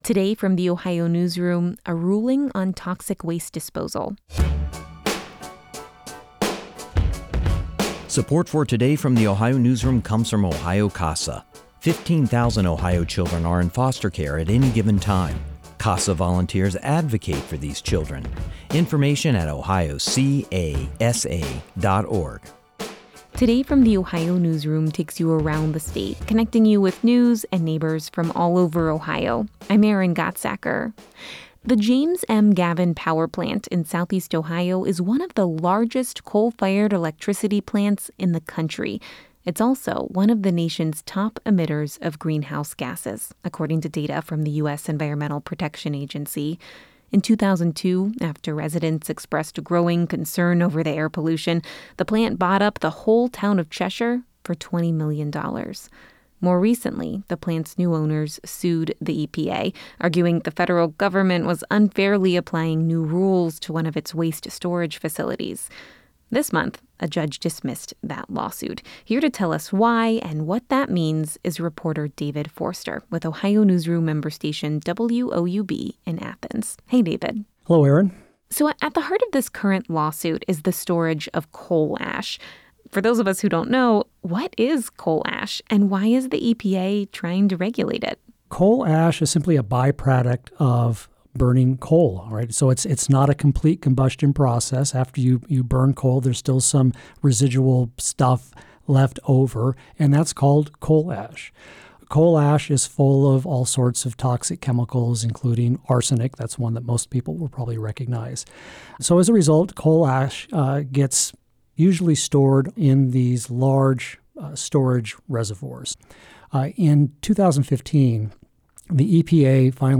This conversation has been lightly edited for brevity and clarity.